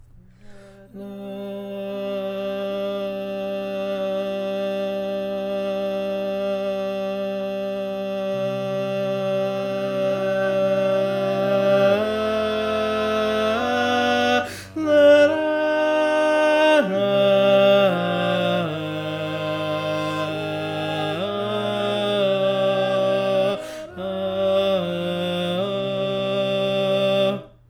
Key written in: E♭ Major
How many parts: 4
Type: Barbershop